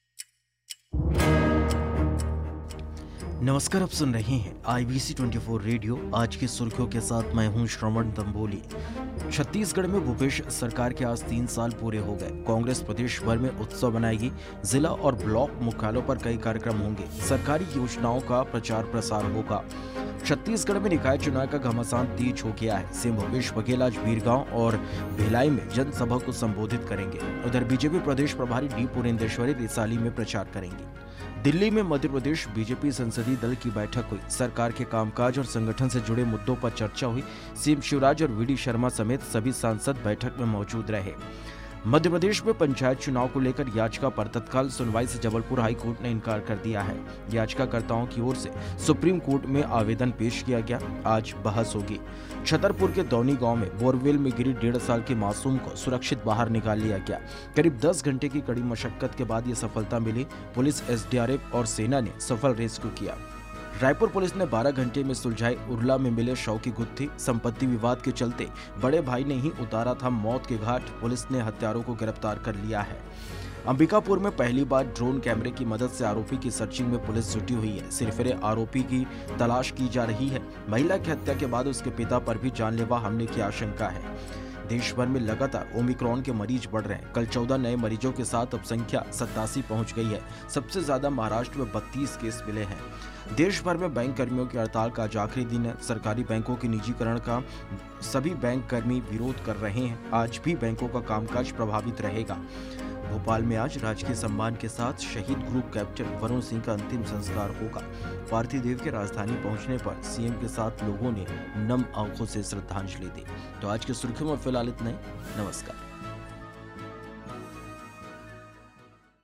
आज की सुर्खियां